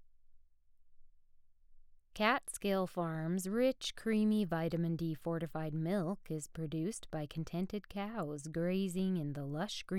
Test 1-I recorded at the same distance from the mic that I did for the chapter I made the original post about.
Test 2-I recorded close to mic 3 inches away.